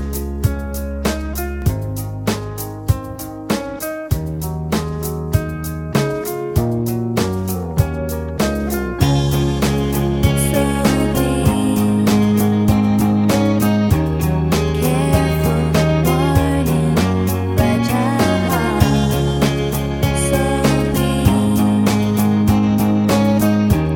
Pop (1990s)